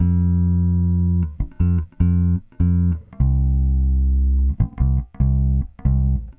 Weathered Bass 04.wav